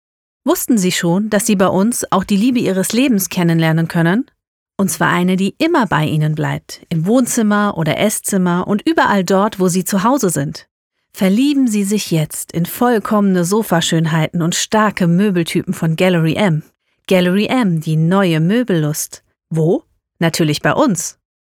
Professionelle Studiosprecherin.
Hier ein Einblick in mein Können und meine Vielseitigkeit: Von ansprechend gesprochenen Werbetexten über atmosphärische Stimmungstexte bis hin zu sachlichen Texten und englischsprachigen Passagen.